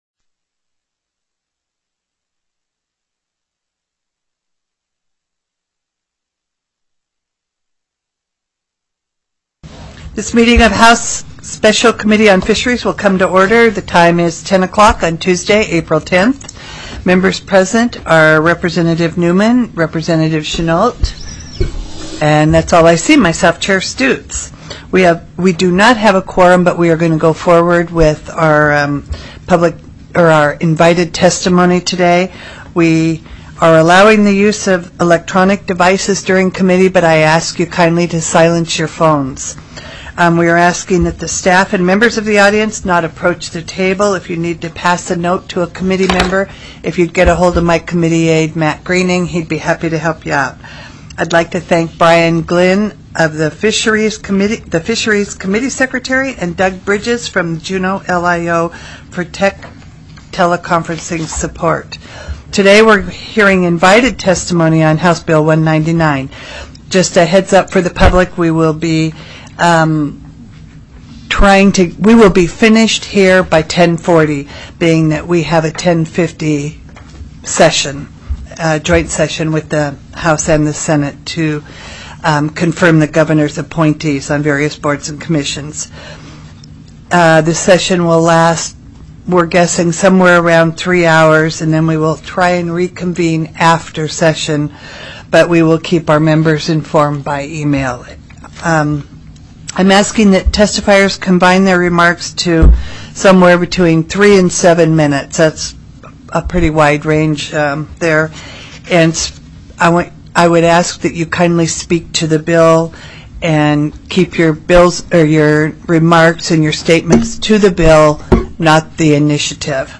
04/10/2018 10:00 AM House FISHERIES